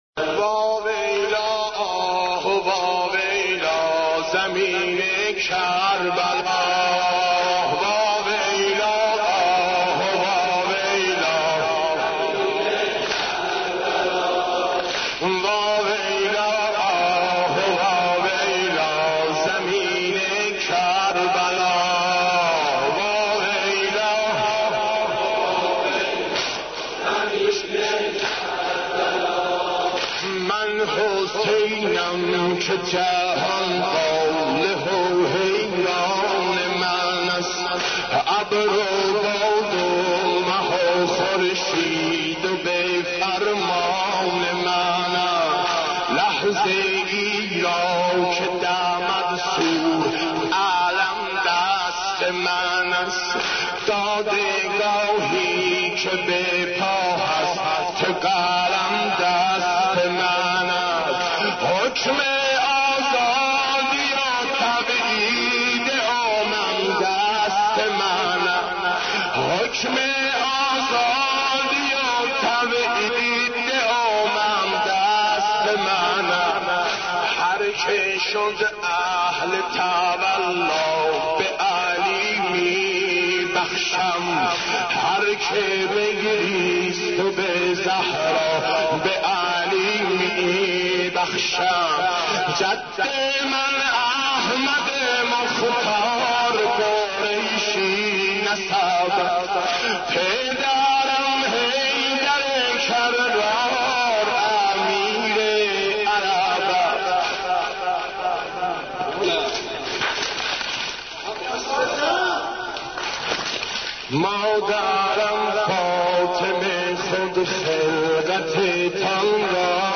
امام حسین ـ واحد 3